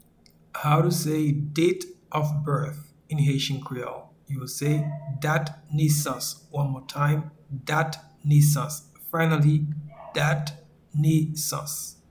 Pronunciation and Transcript:
Date-of-birth-in-Haitian-Creole-Dat-nesans.mp3